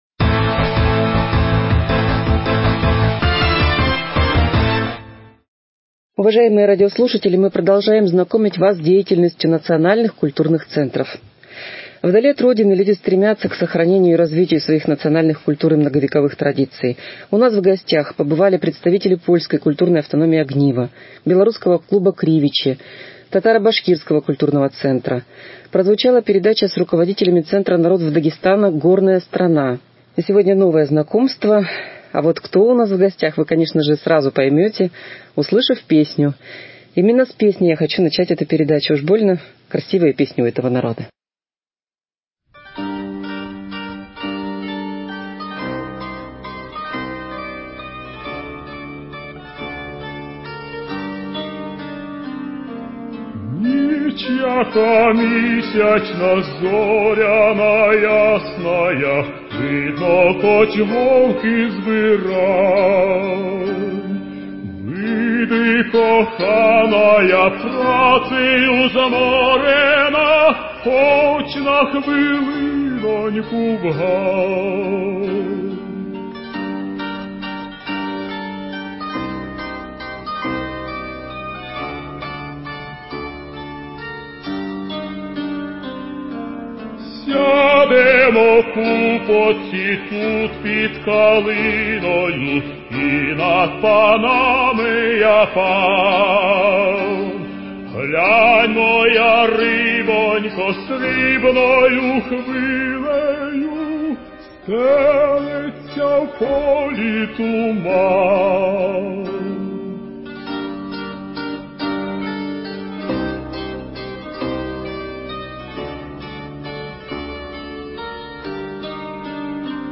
Актуальное интервью: Украинский центр 26.11.2020